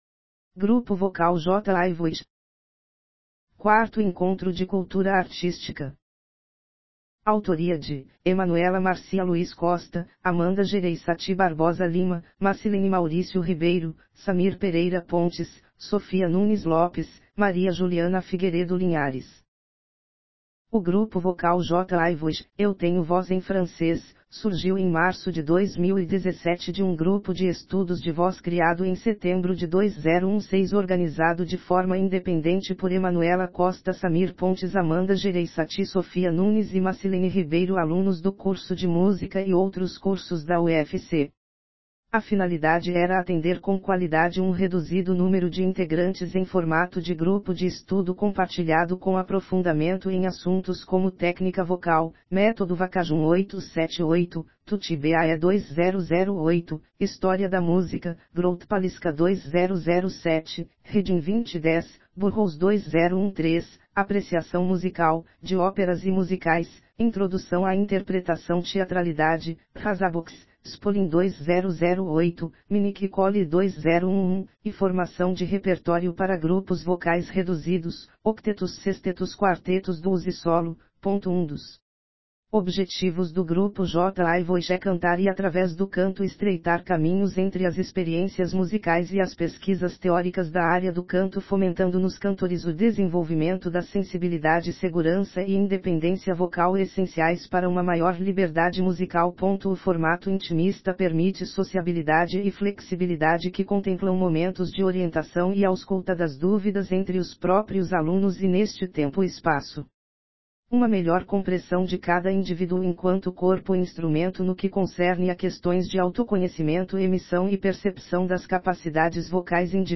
GRUPO VOCAL J’AI VOIX | Encontros Universitários da UFC
IV Encontro de Cultura Artística